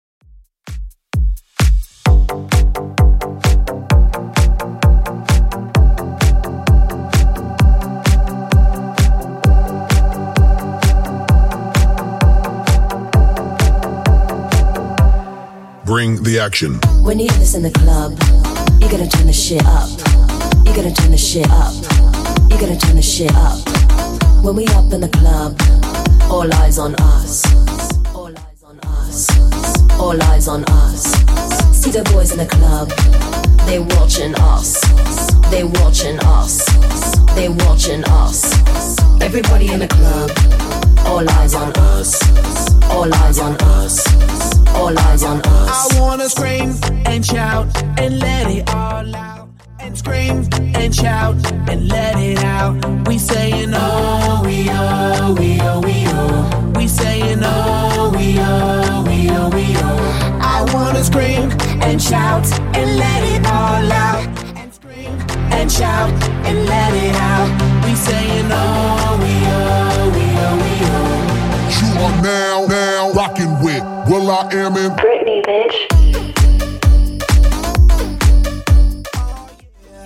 Genre: 80's
BPM: 130